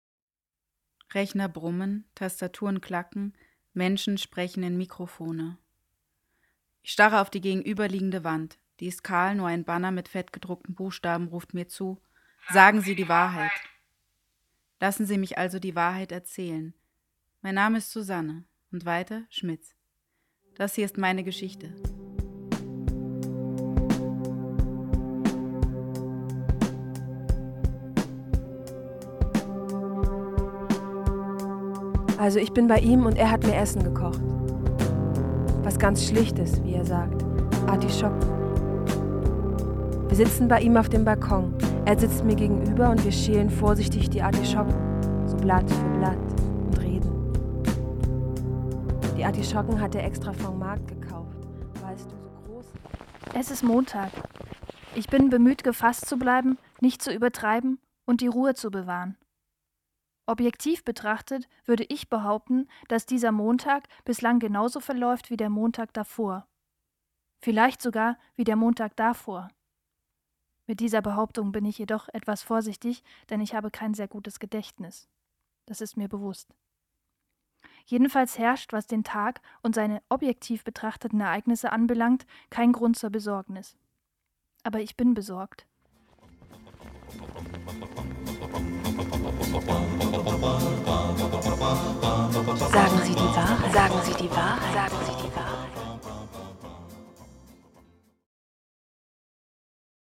Dieser Beitrag wurde unter LESUNGEN veröffentlicht.